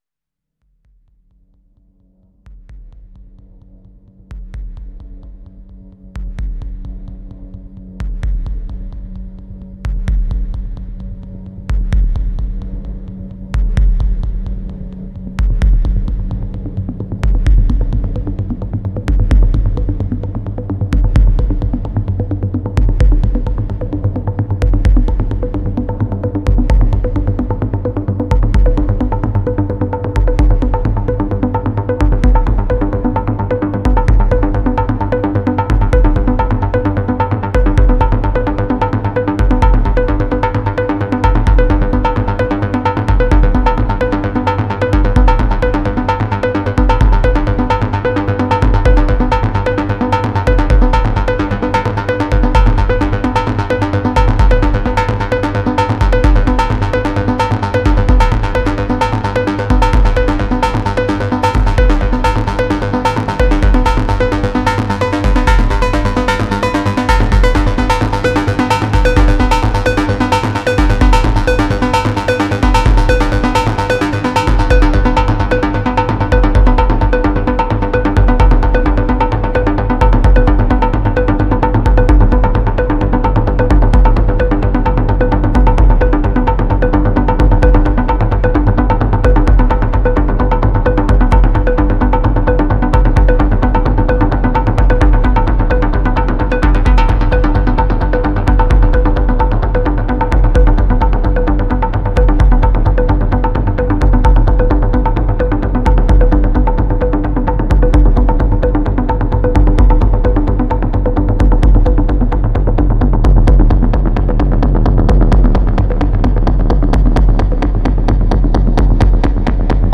描述：奇怪的中场休息
标签： 模拟 电子 合成器 EURORACK 模块化 硬件 振荡器 合成器
声道立体声